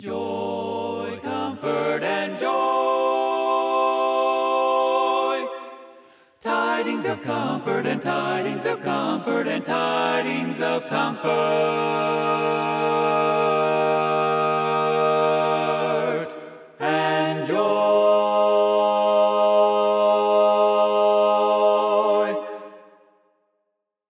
Key written in: G Minor
Type: Barbershop